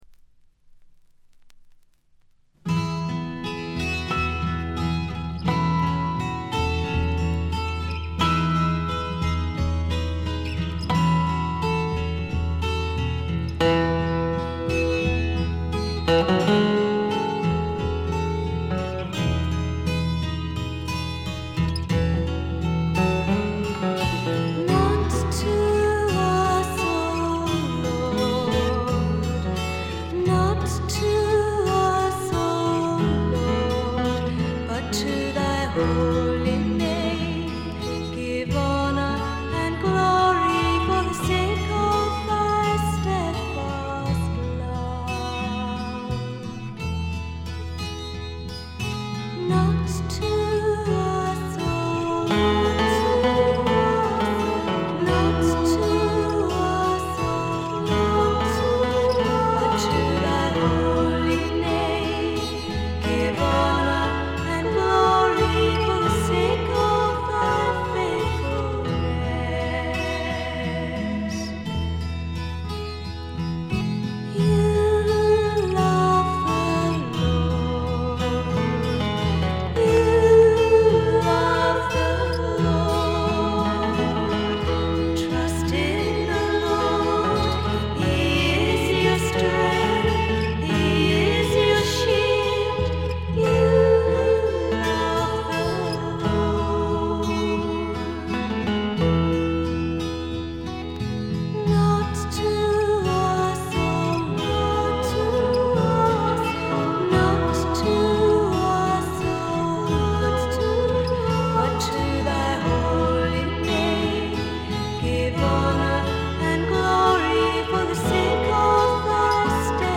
妖精フィメール入り英国ミスティック・フォーク、ドリーミー・フォークの傑作です。
霧深い深山幽谷から静かに流れてくるような神秘的な歌の数々。
それにしても録音の悪さが幸いしてるのか（？）、この神秘感は半端ないです。
試聴曲は現品からの取り込み音源です。